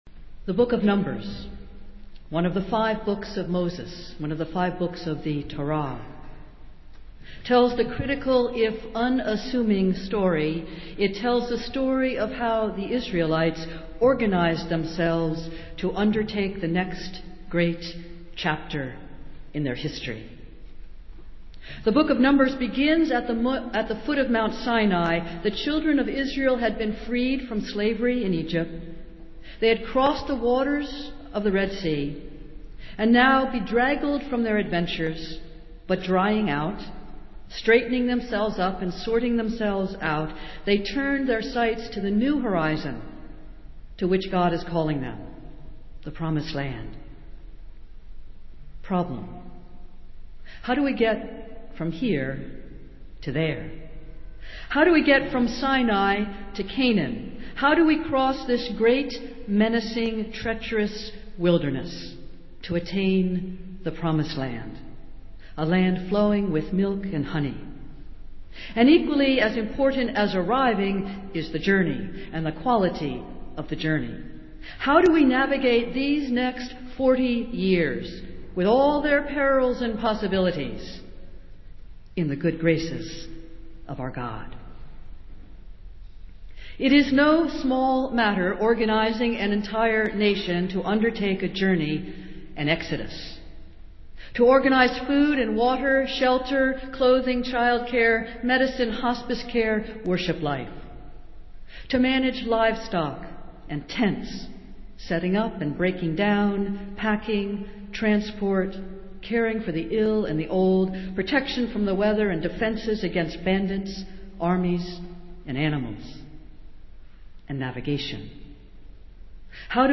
Festival Worship - 342nd Annual Meeting Sunday